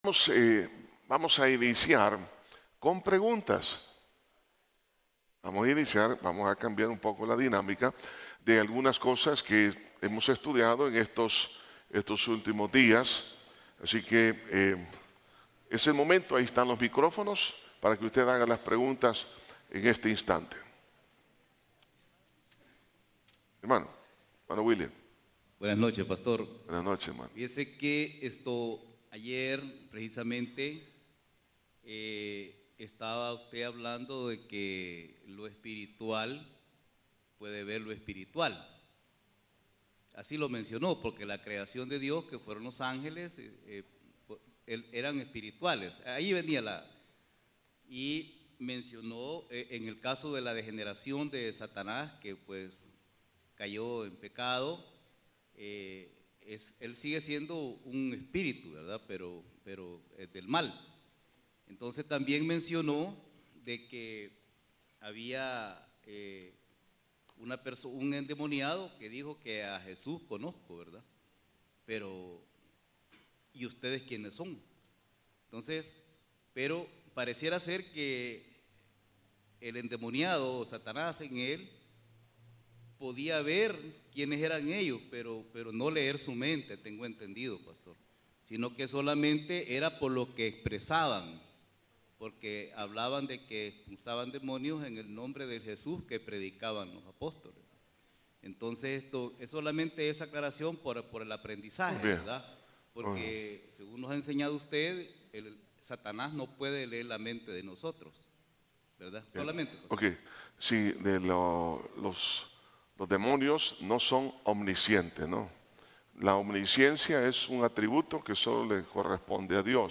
Estudio de Cristologia